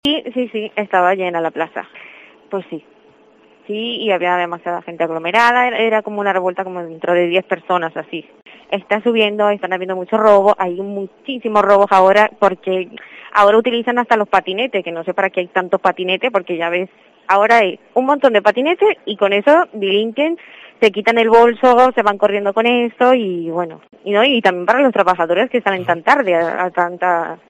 Una trabajadora de un local ubicado en la Plaça de la Font explica los hechos y el aumento de robos en la zona